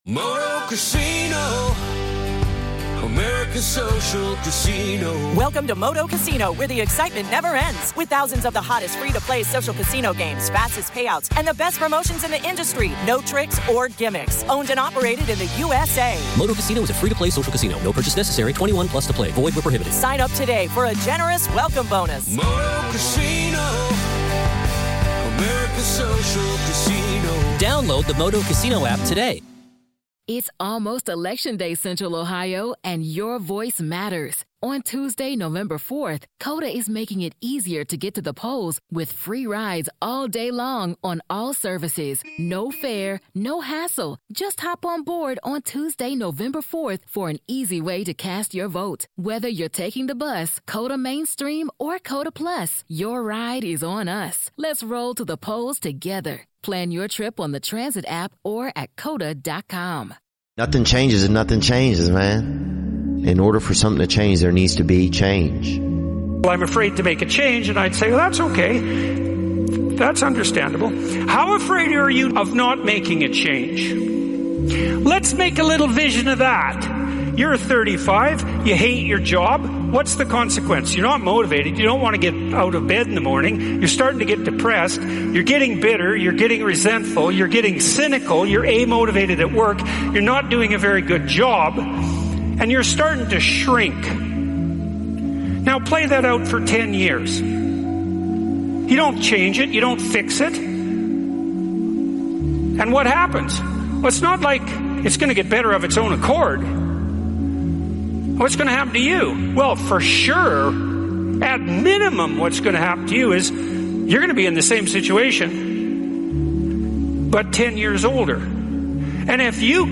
This Episode is a compilation of some of the best motivational speeches from legends like David Goggins, Brian Tracy, and Jim Rohn. It's designed to push you out of your comfort zone and encourage you to take action now.